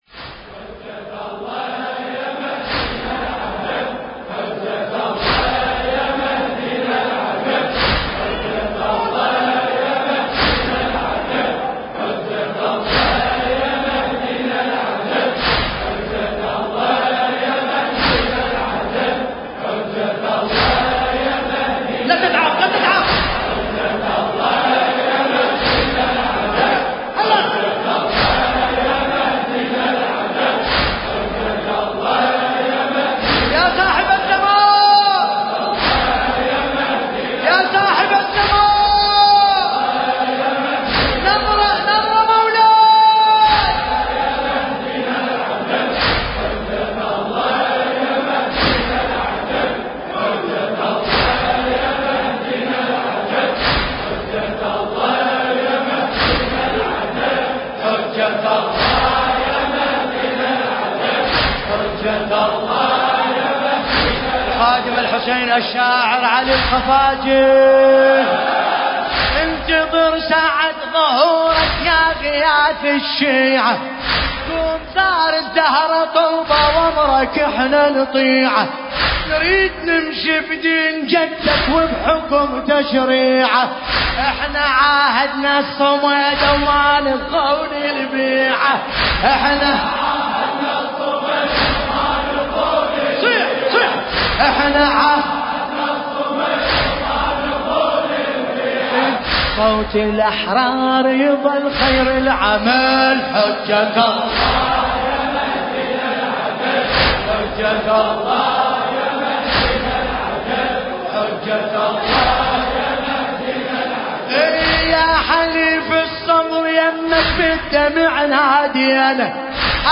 المكان: هيئة وحسينية الصديقة الطاهرة (عليها السلام)/ الديوانية – الحمزة الشرقي
القارئ : الحاج باسم الكربلائي